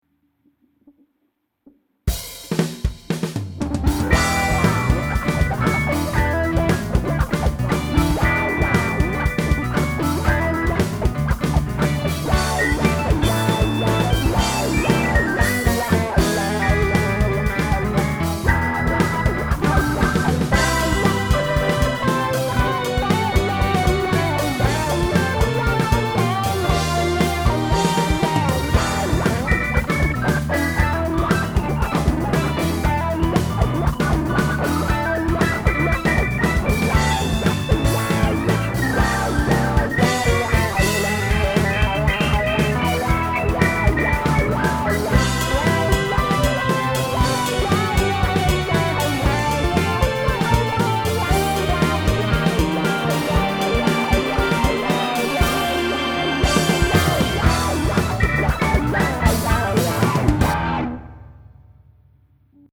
ファンク１